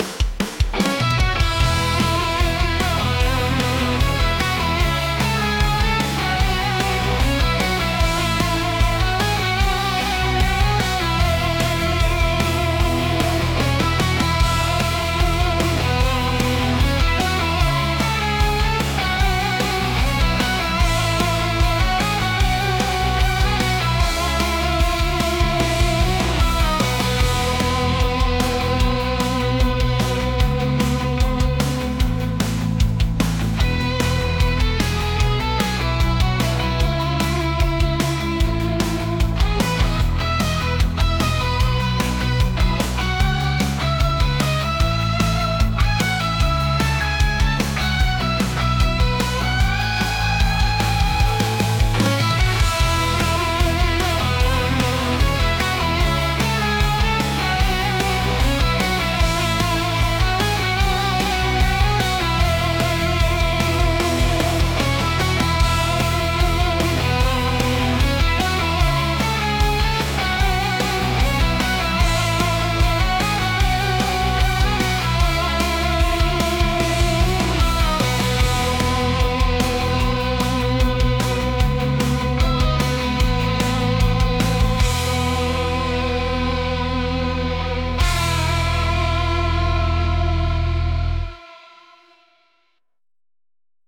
rock | cinematic | corporate